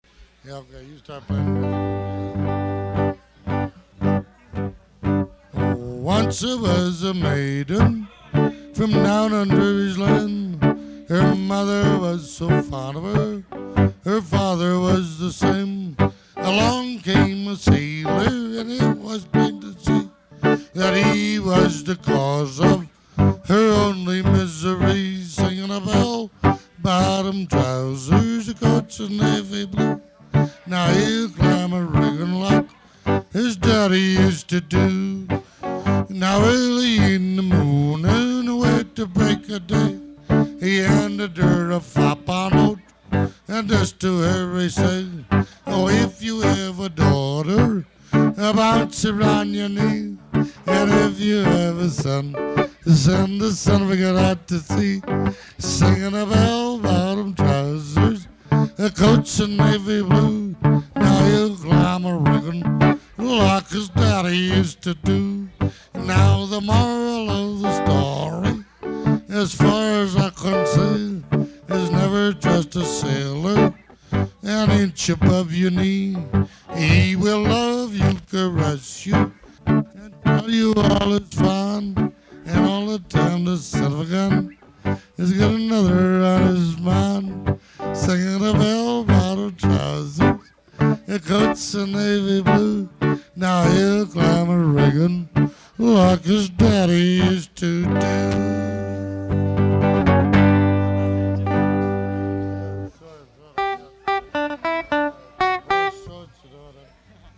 Traditional Sea Shanty